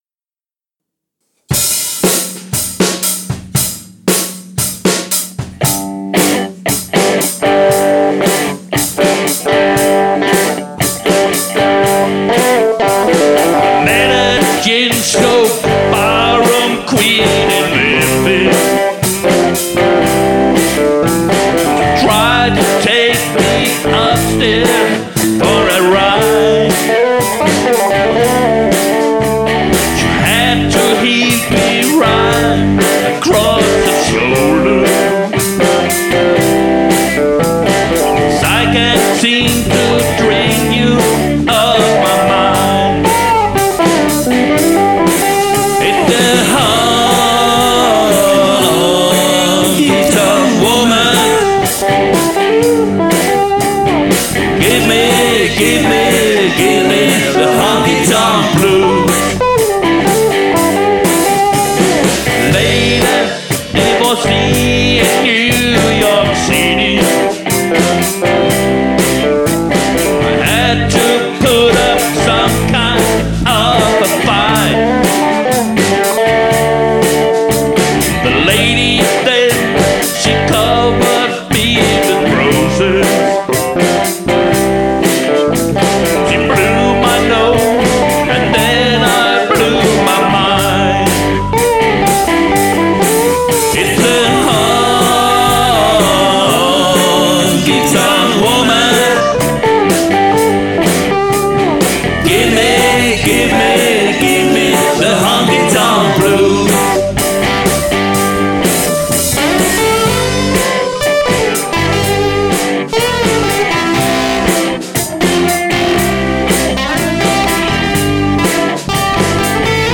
• Coverband
• Rockband